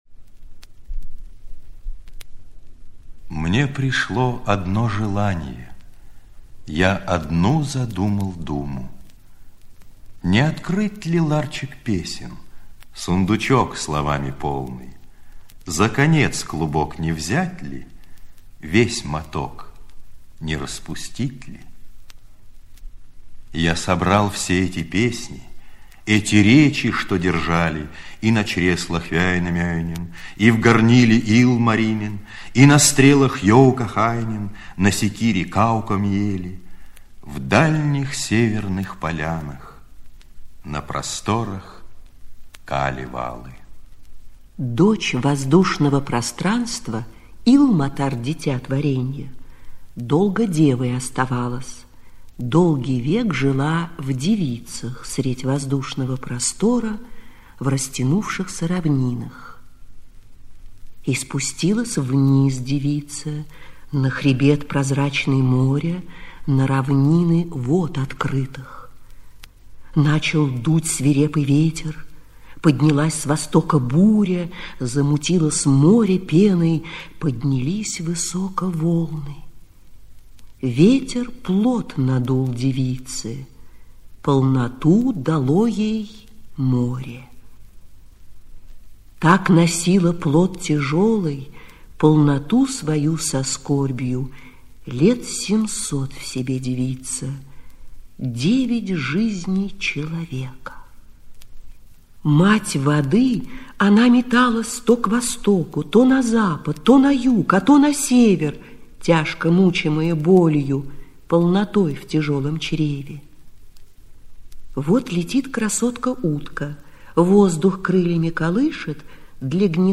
Слушать онлайн аудиокнигу "Калевала" в сокращении:
Здесь вы можете слушать онлайн карело-финский эпос "Калевала" с песнями народов Финляндии и Карелии.